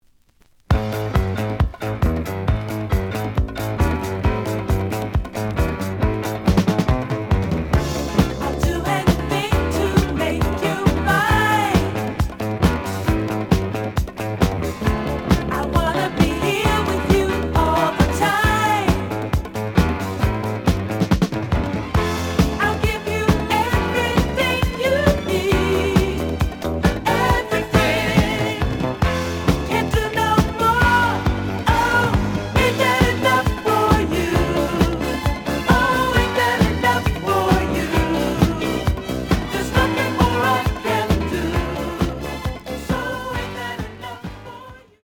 The audio sample is recorded from the actual item.
●Genre: Disco
Some click noise on middle of A side, but almost good.)